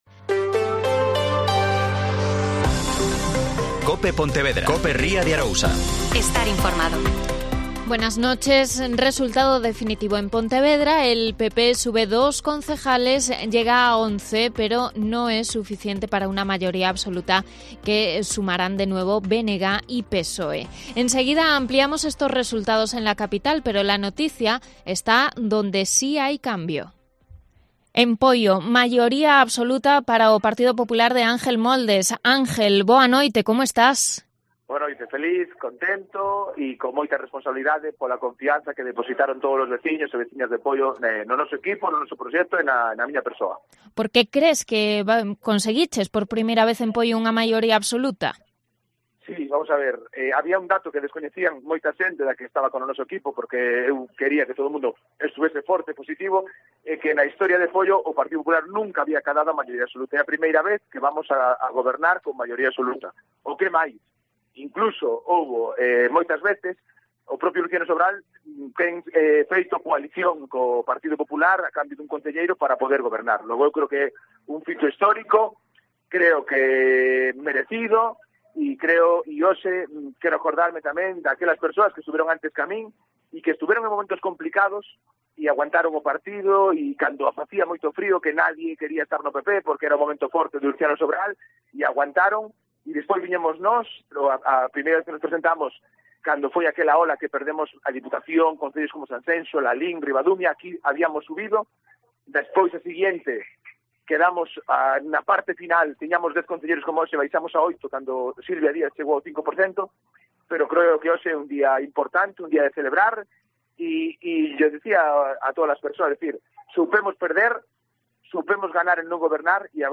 Especial Elecciones Municipales 2023 (Informativo 23,23h)